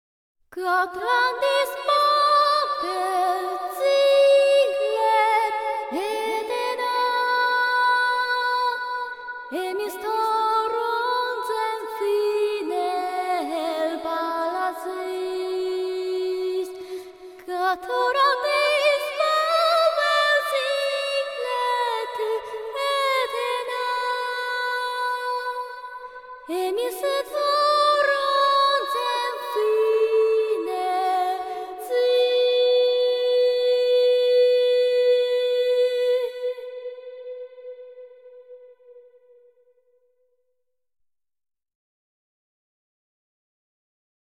The song that is sung for Hibiki's